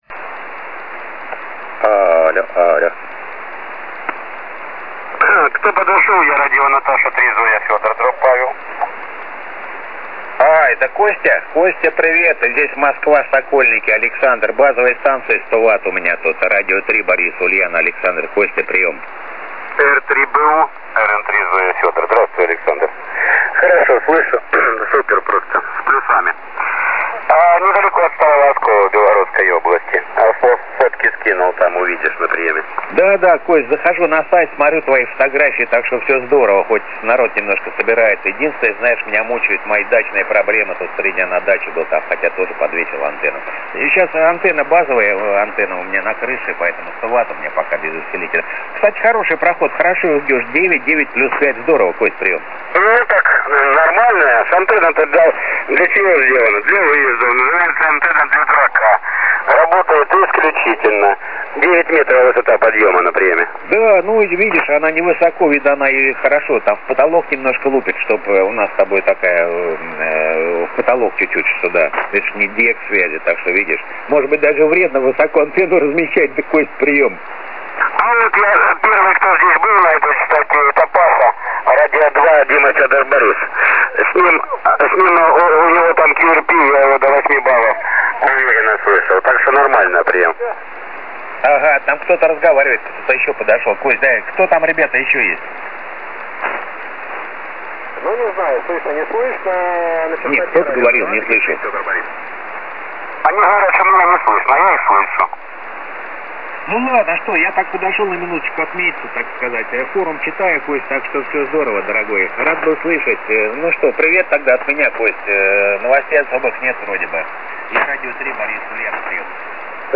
7175 кГц, р.любители из полевых условий 24.05.2014
Начало » Записи » Записи радиопереговоров - любители и пираты